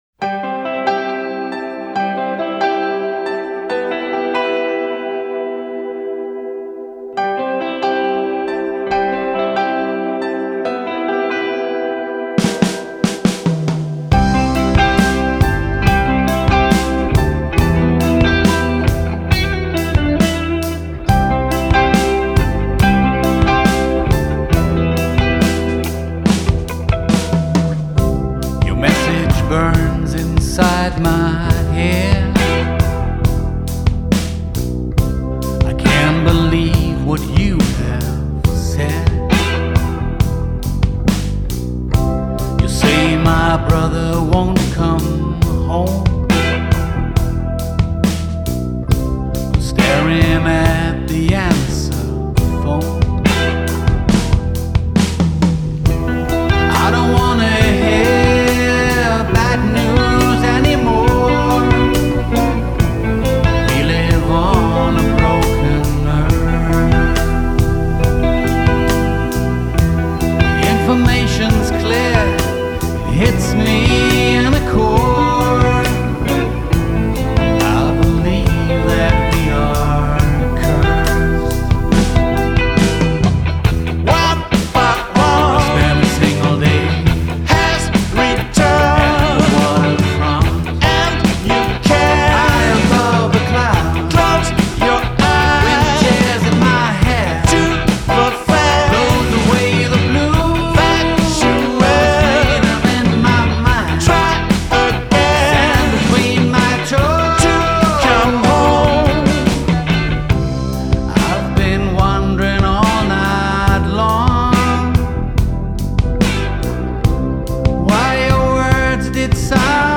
Mai 2024, ist eine Rock-EP mit drei Songs.